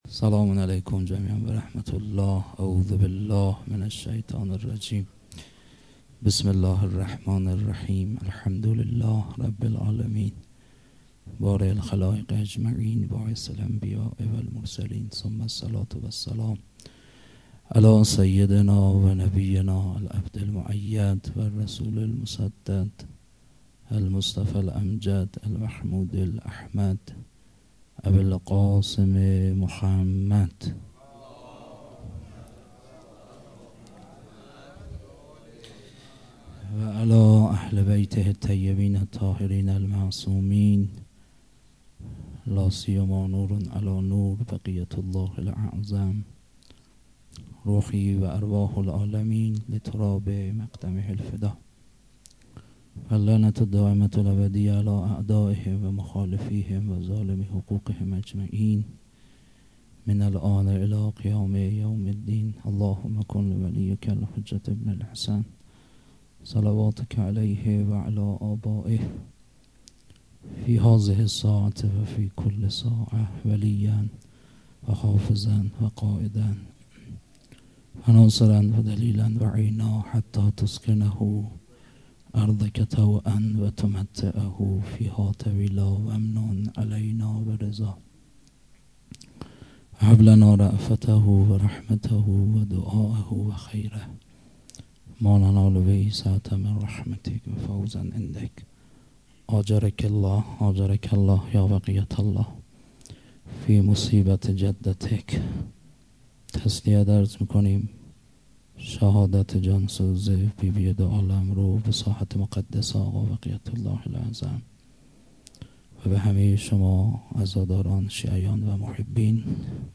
هیئت مکتب الزهرا(س)دارالعباده یزد
فاطمیه دوم_ظهر شهادت